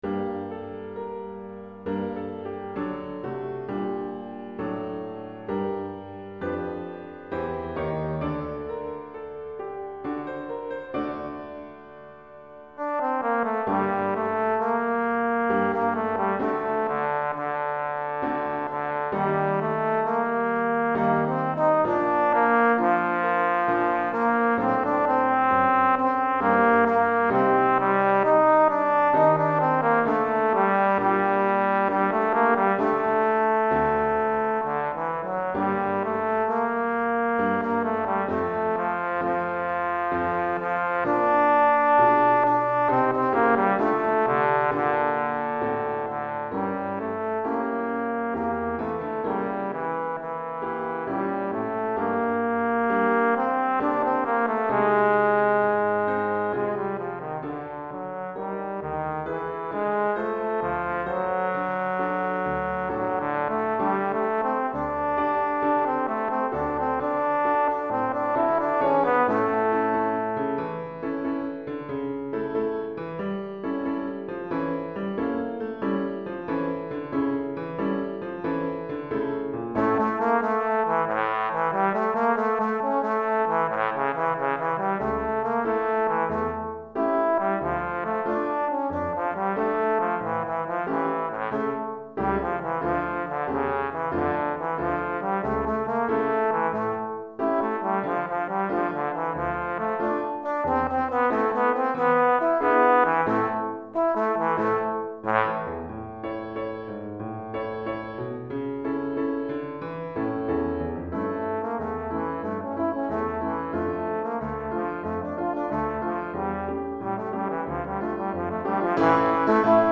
Trombone et Piano